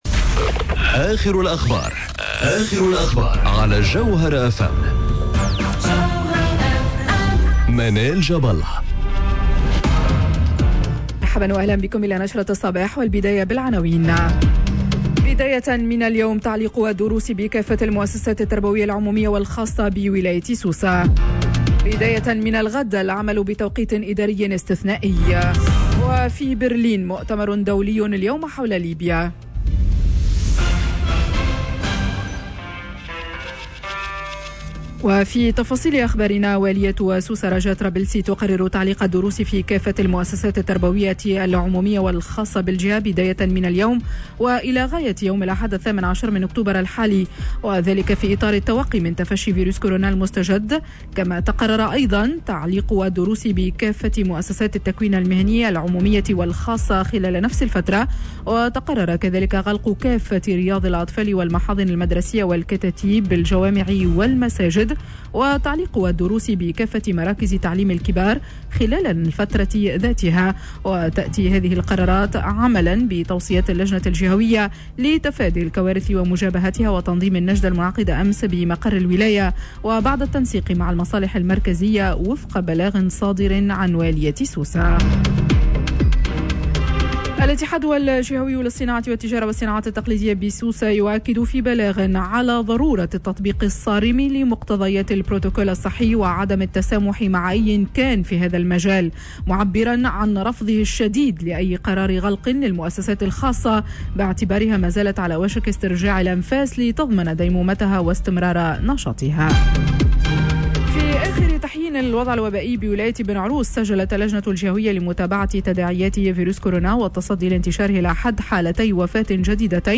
نشرة أخبار السابعة صباحا ليوم الإثنين 05 أكتوبر 2020